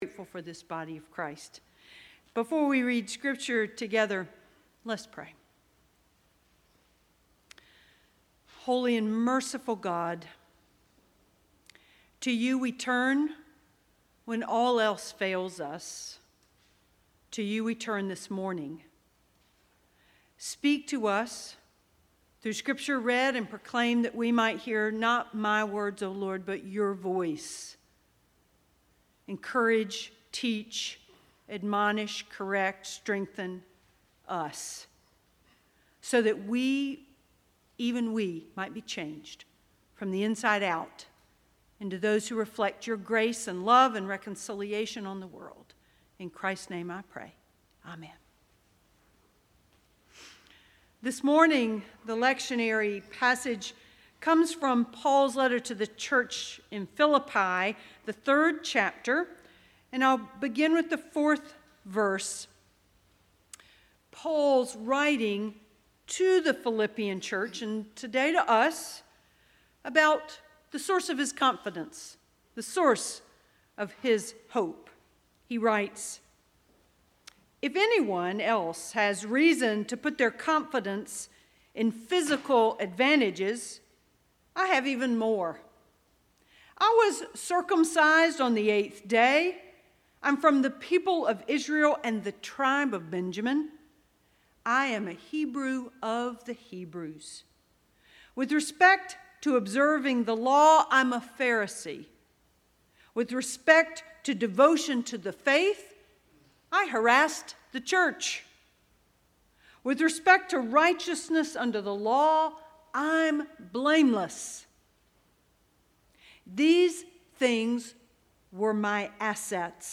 Sermon Archive - Forest Lake Presbyterian Church